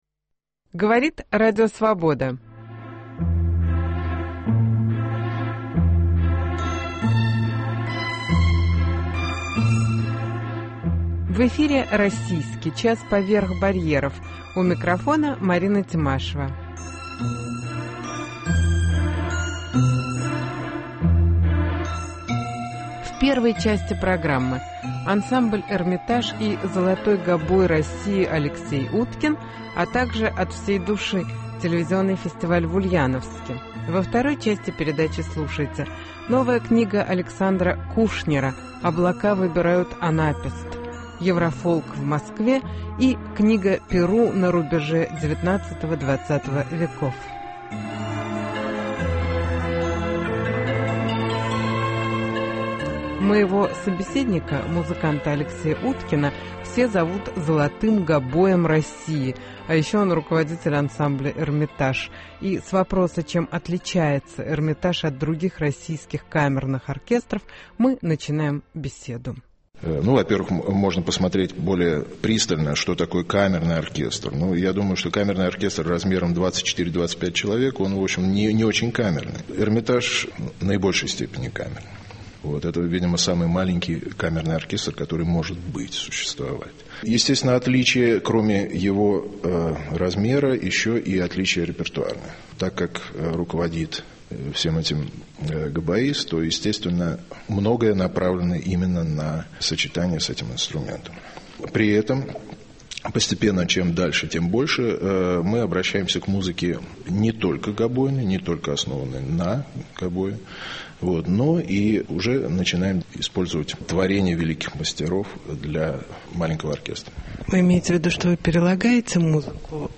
Новая книга стихов Александра Кушнера "Облака выбирают анапест". Интервью с Алексеем Уткиным ( "золотой гобой" России).